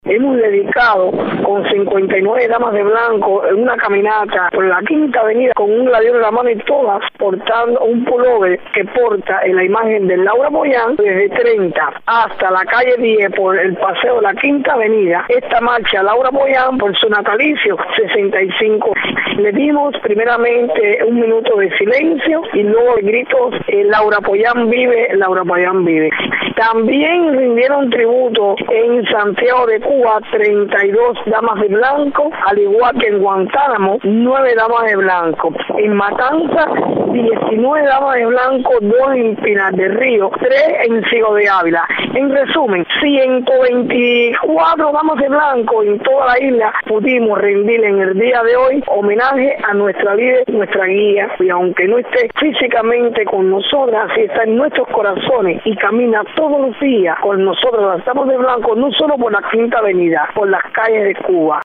Las Damas de Blanco oraron por la libertad del pueblo en la Iglesia de Santa Rita en La Habana, y retomaron su marcha por el Paseo de la Quinta Avenida en Miramar, la ruta que seguían cuando su líder Laura Pollán vivía. La representante del movimiento, Berta Soler, explica cómo transcurrió esta jornada.